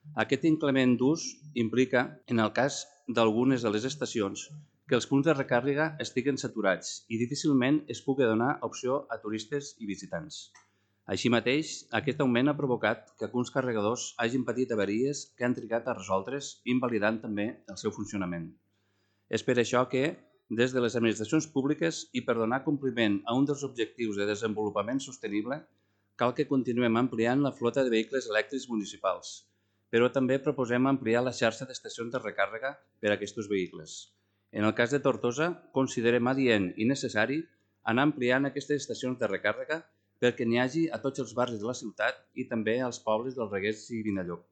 Segons el regidor de Junts, Jordi Sorolla, els quatre punts de recàrrega que hi ha a la ciutat han triplicat el seu ús des del 2022, registrant gairebé 100.000 kw amb més de 4.000 connexions.